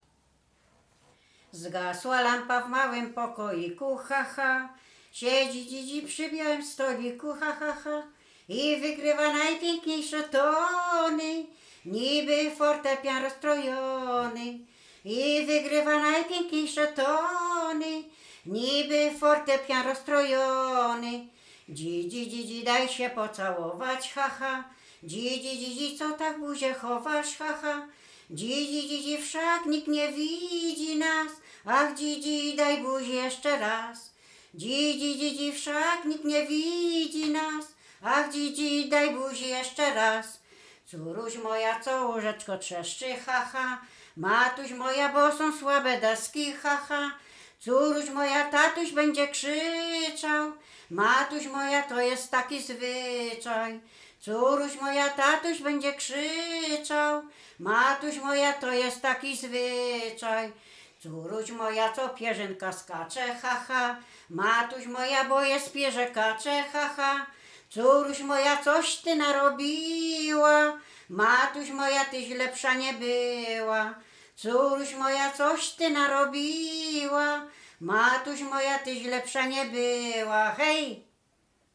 Sieradzkie
miłosne liryczne pieśni piękne